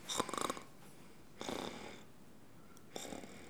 Les sons ont été découpés en morceaux exploitables. 2017-04-10 17:58:57 +02:00 602 KiB Raw History
ronflement_03.wav